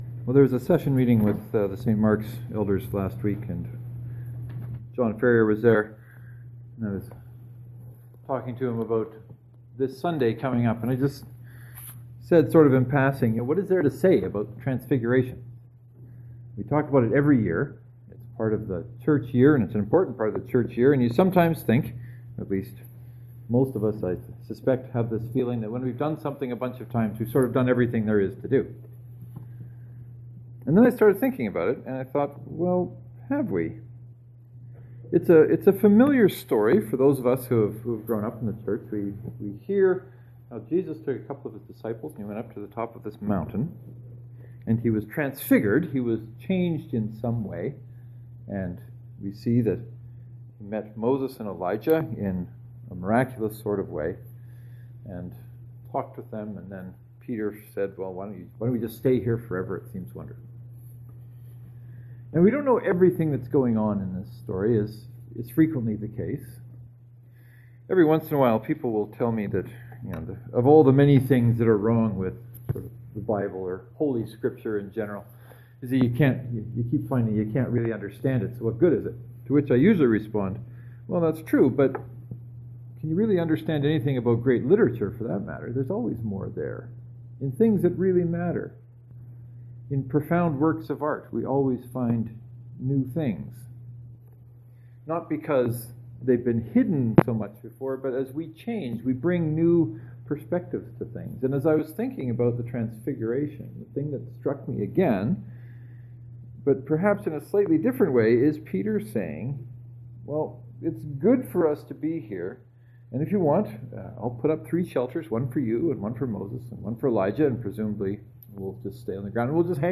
Knox Presbyterian Can we stay on the mountain?